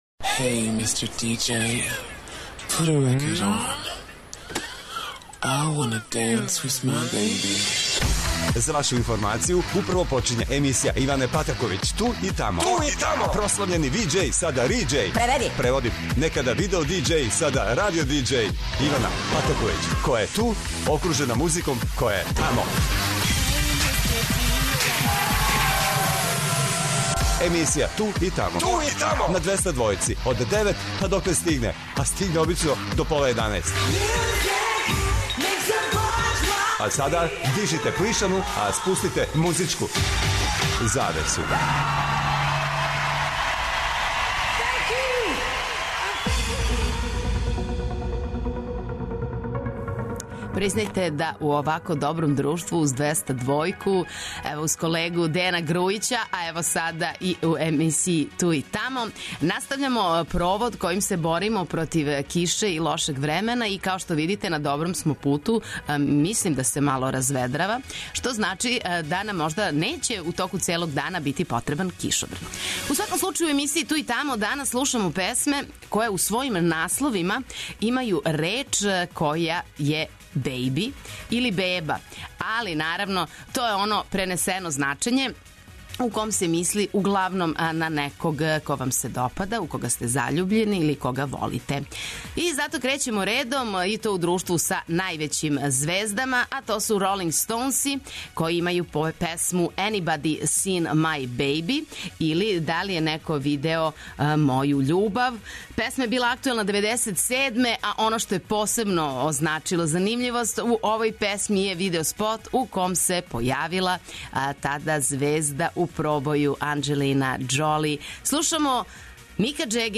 Док се будите и пијете јутарњу кафу обавезно појачајте 'Двестадвојку' јер вас очекују велики хитови страни и домаћи, стари и нови, супер сарадње, песме из филмова, дуети и још много тога.